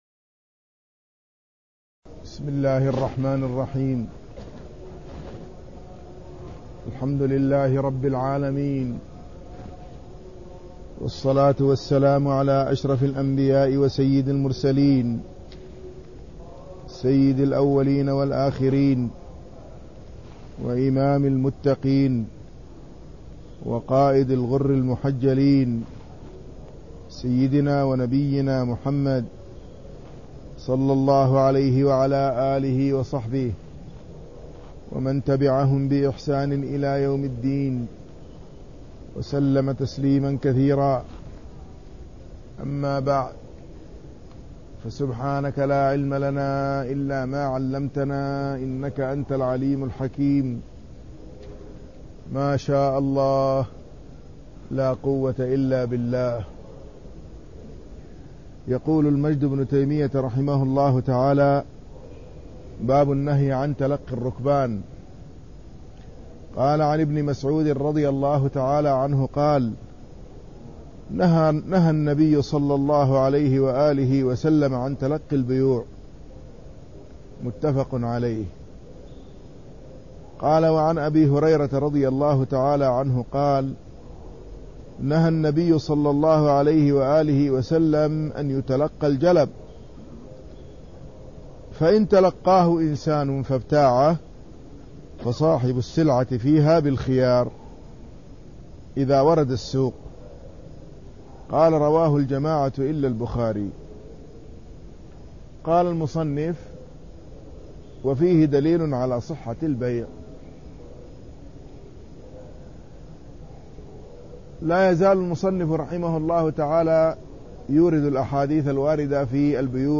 تاريخ النشر ٢٦ جمادى الأولى ١٤١٨ هـ المكان: المسجد النبوي الشيخ